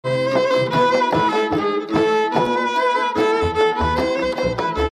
Here is an example of a loud pop:
A LOUD POP ➔ click to hear it